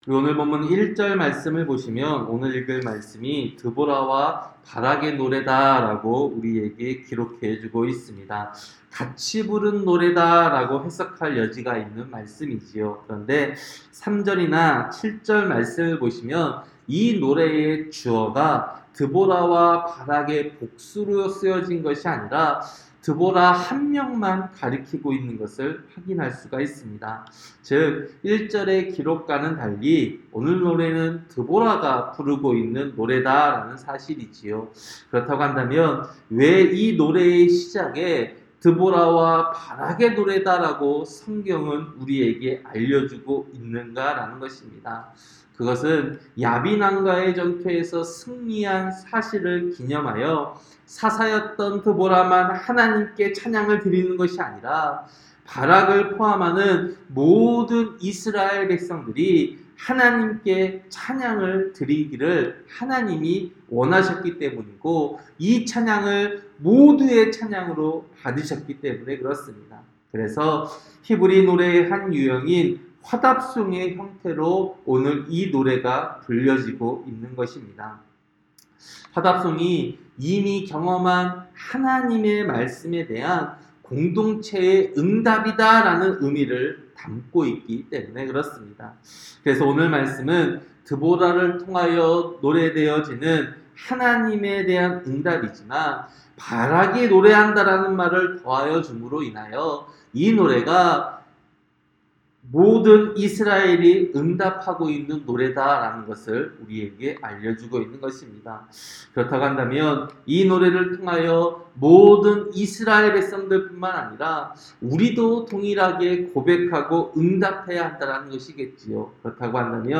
새벽설교-사사기 5장